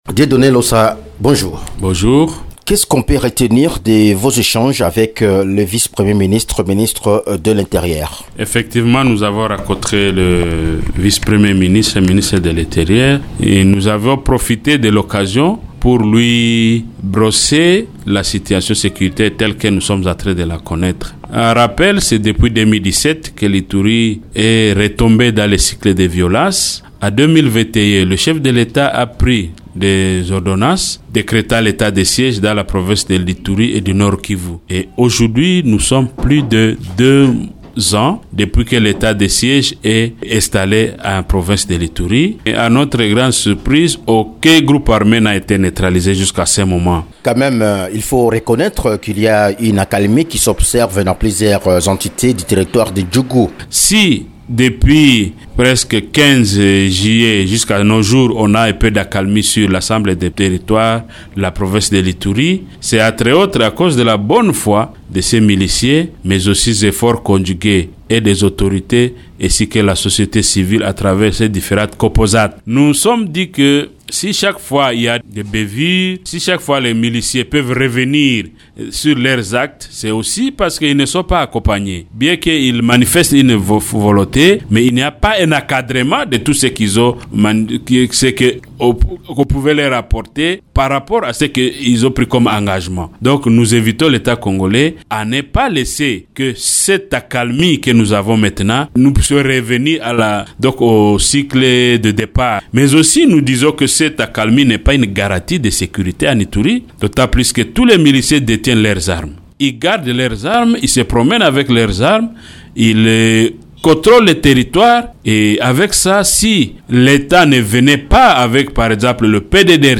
Invité de Radio Okapi, il regrette qu’aucun groupe armé ne soit neutralisé à plus de deux ans après que la gestion de la chose publique a été confiée aux forces de l’ordre.